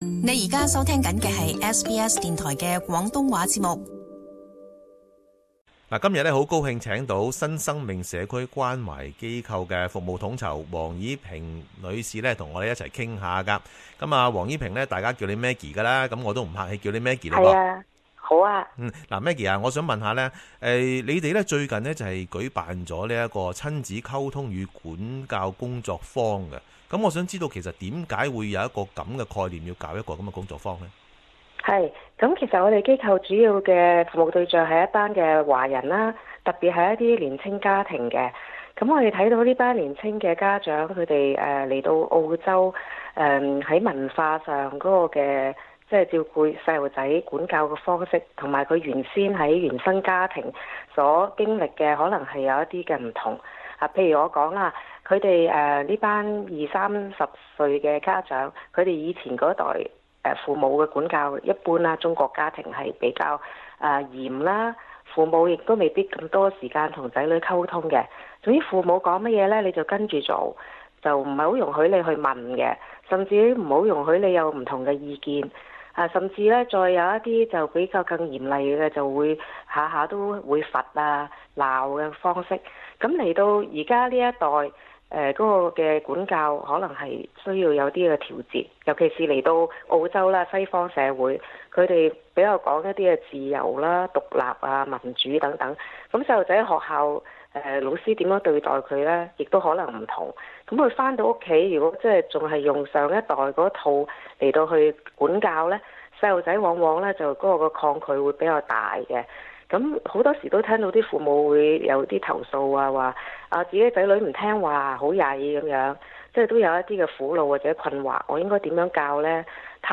【社团专访】新生命社区关怀亲子沟通与管教工作坊